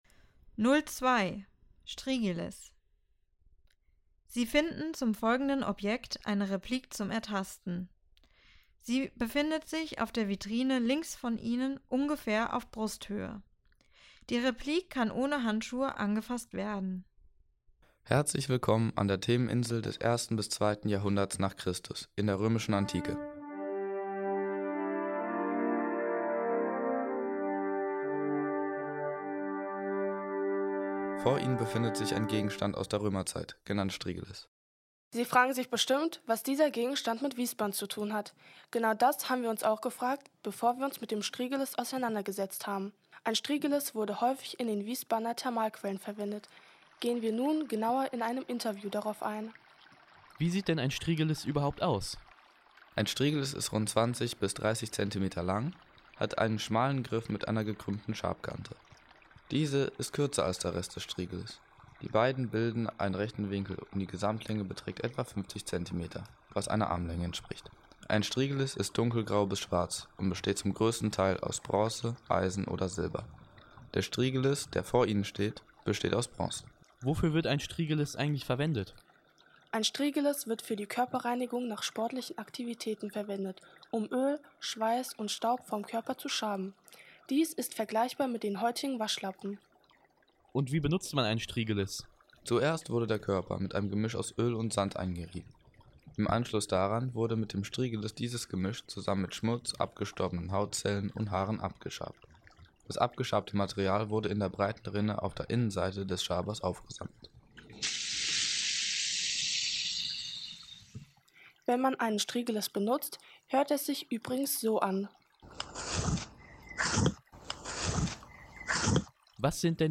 Audioguide - Strigilis